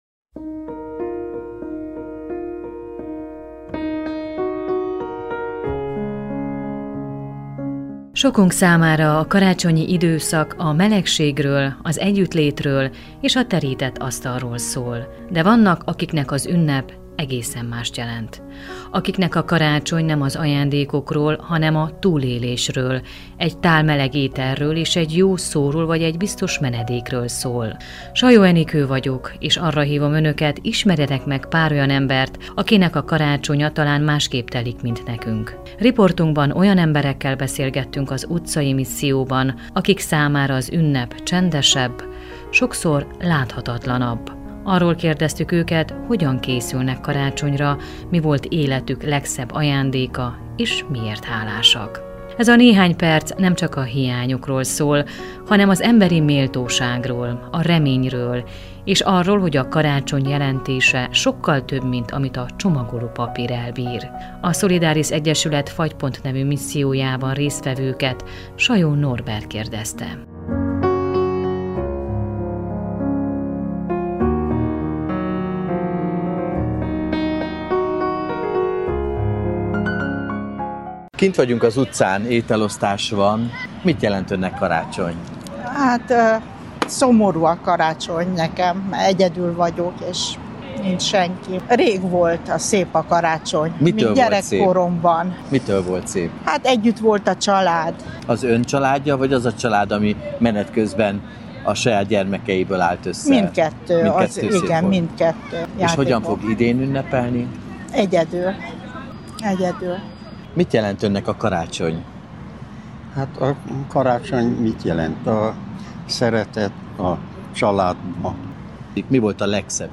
Az itt megfogalmazott gondolatok Marosvásárhely és Kolozsvár közterein, ételosztás során hangzottak el.